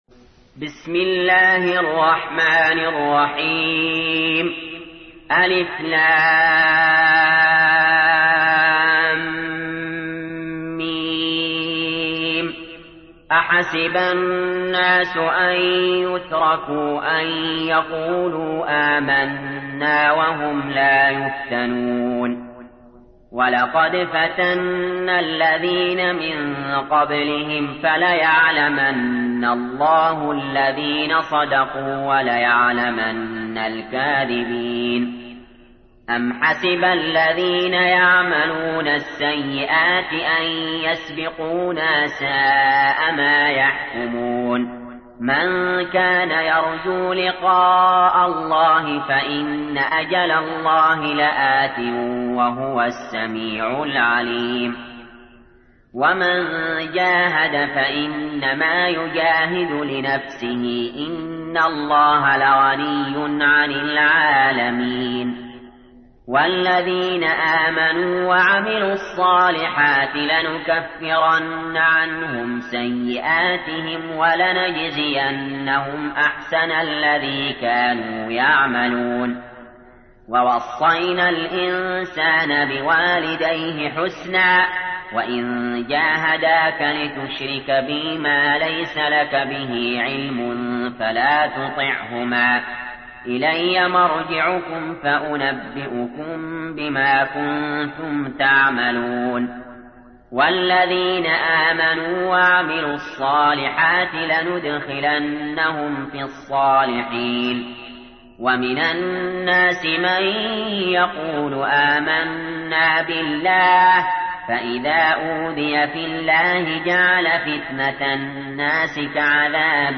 تحميل : 29. سورة العنكبوت / القارئ علي جابر / القرآن الكريم / موقع يا حسين